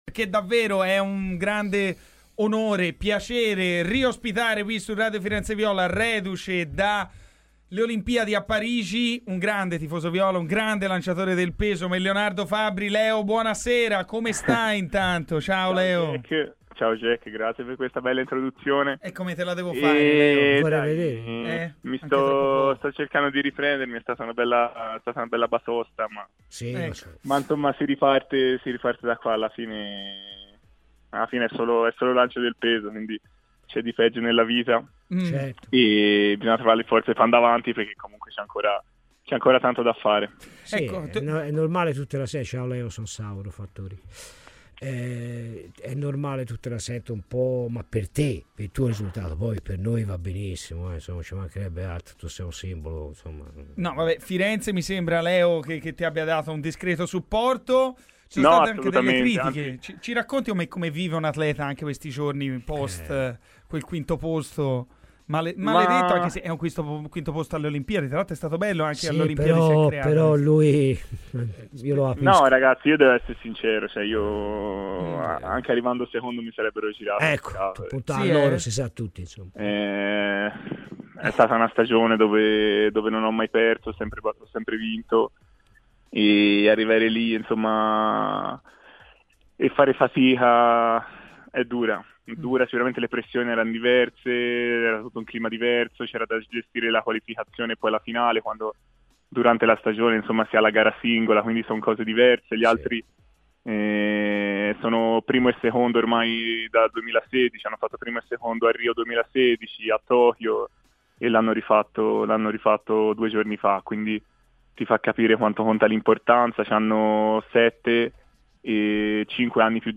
Gli ho detto se lo prendete ti ammazzo (ride, ndr)" ASCOLTA QUI L'INTERVISTA COMPLETA